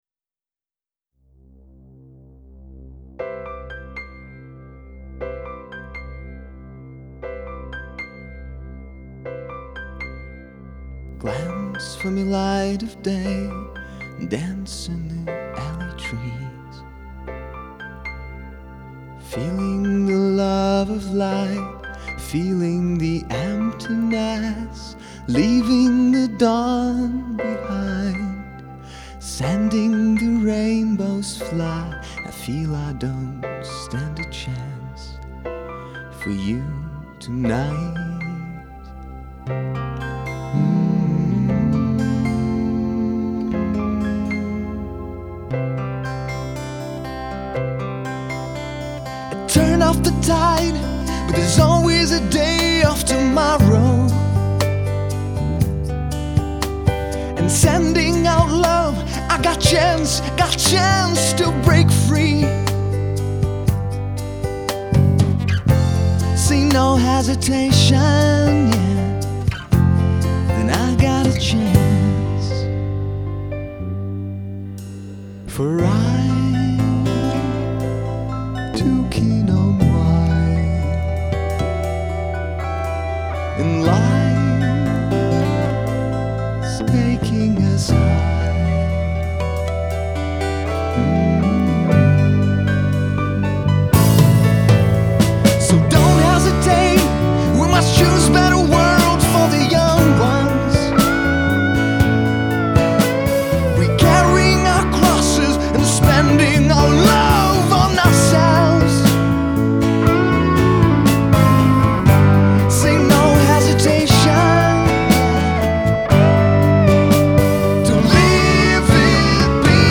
and acoustic guitars